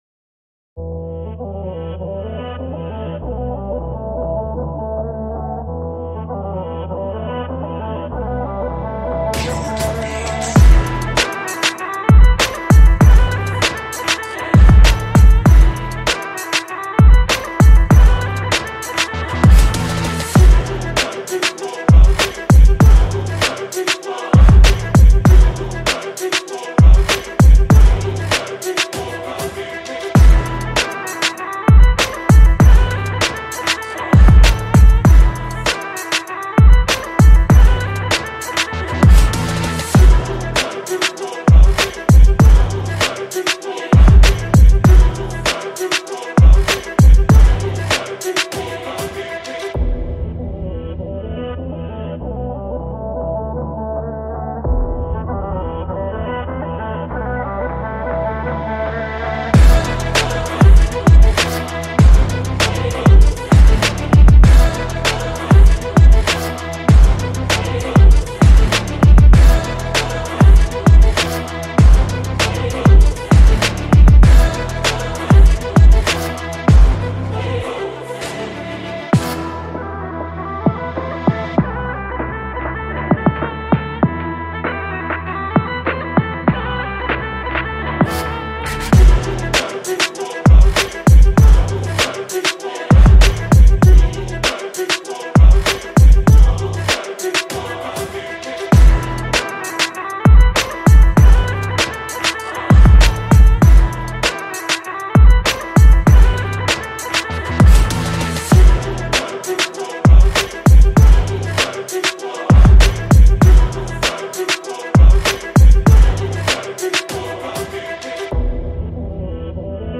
Ethnic_Balkan_prod.mp3